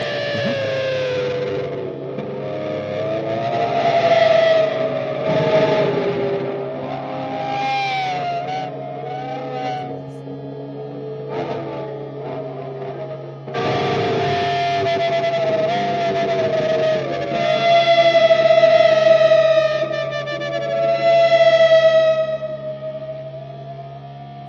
more electric guitar string bending.... sounds like whales, again...